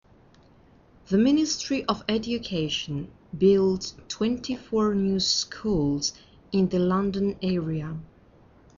Escucha a la Redactora Jefe y completa las noticias con las siguientes palabras: